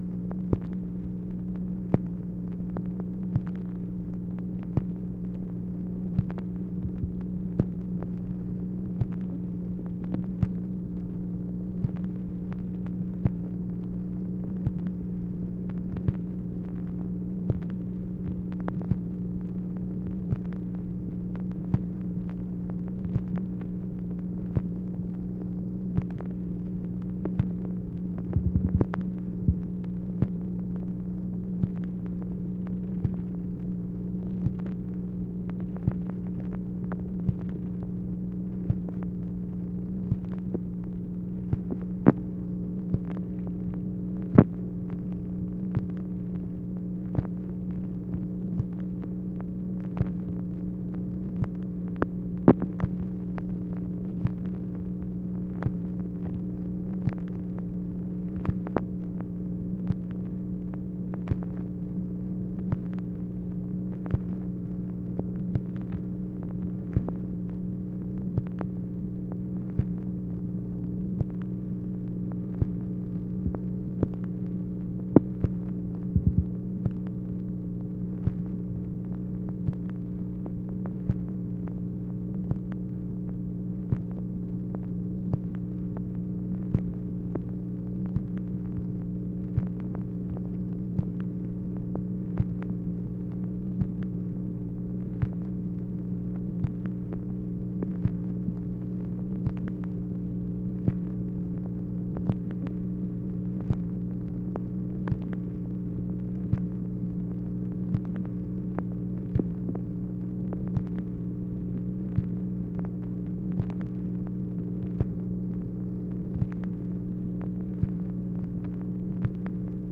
MACHINE NOISE, January 31, 1964
Secret White House Tapes | Lyndon B. Johnson Presidency